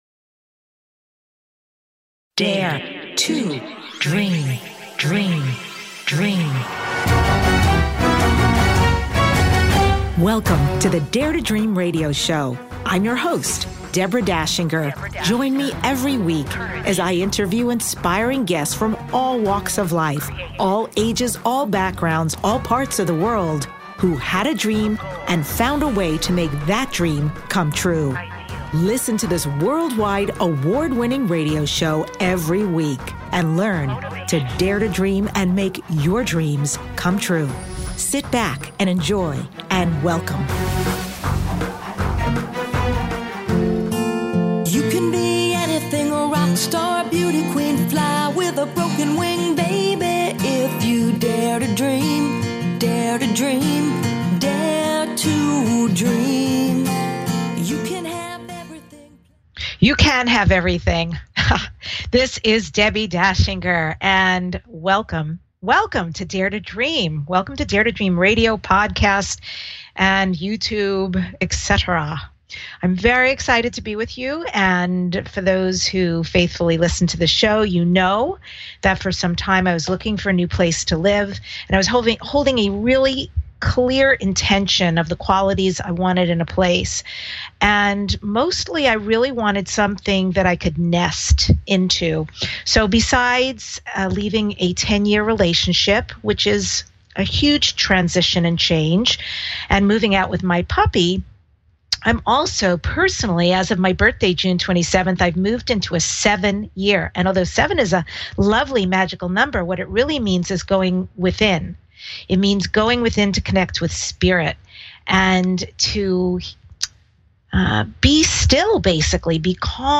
Dare To Dream Talk Show